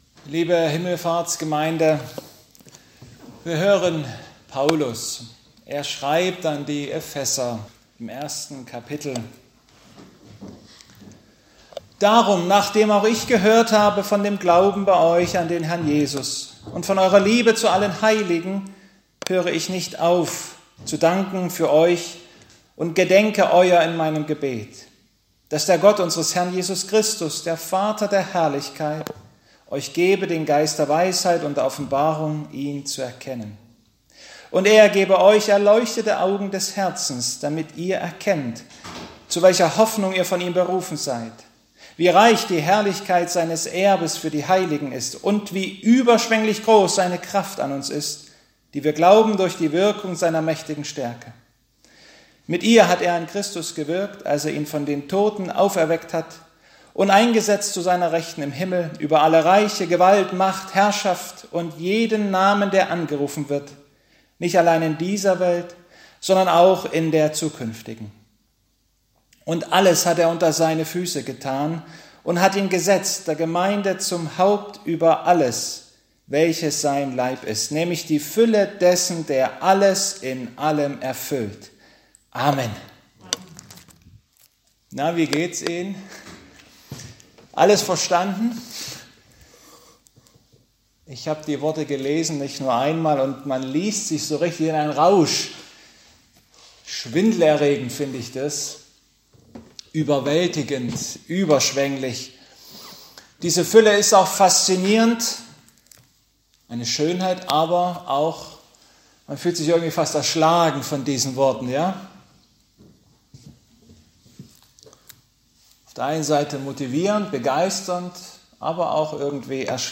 Gottesdienstart: Predigtgottesdienst